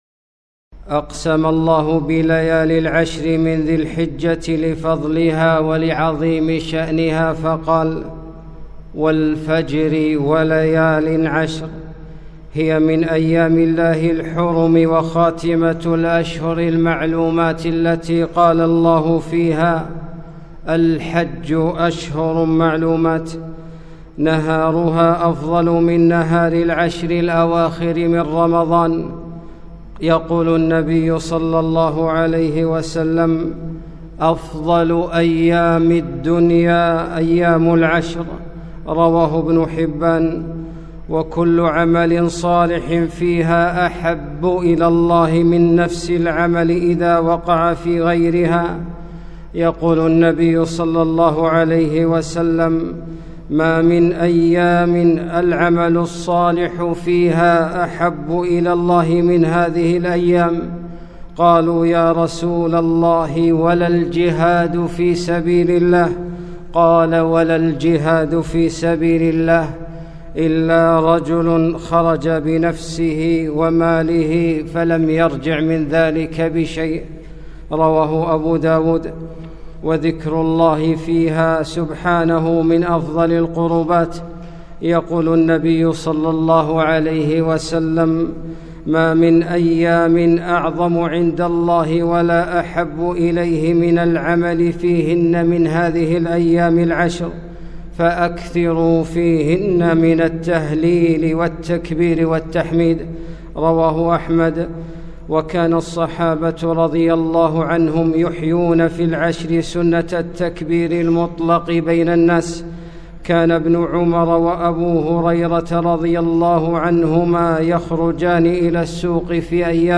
خطبة - فضائل وأحكام العشر